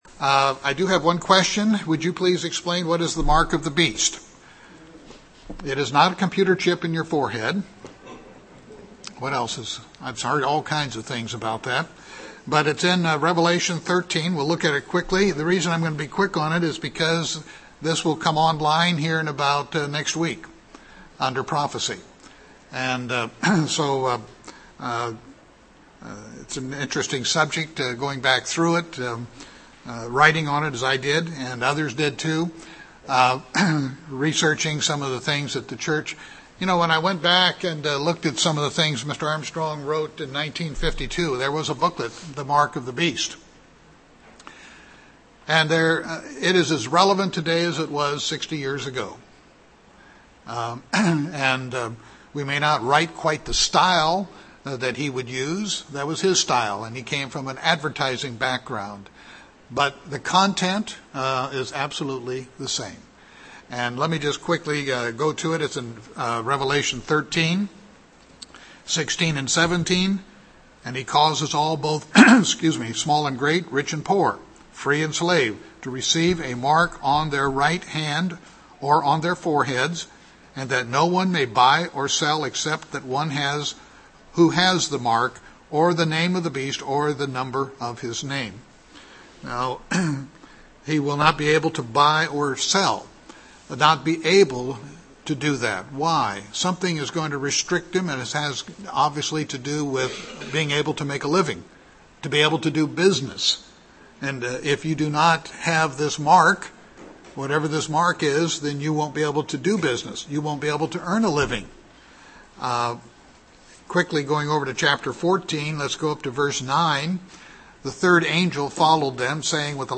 6-16-12 bible study.mp3